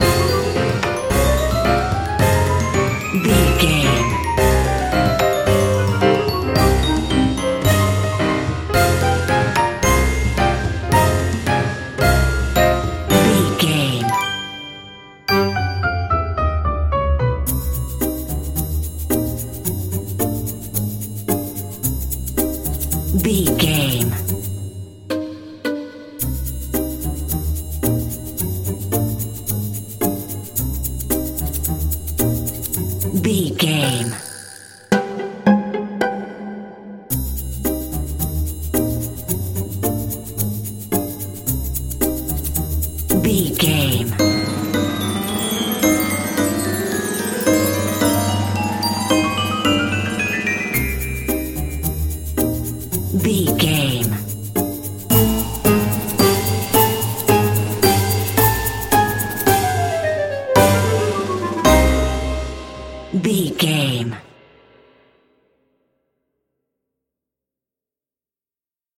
Aeolian/Minor
percussion
strings
circus
goofy
comical
cheerful
perky
Light hearted
quirky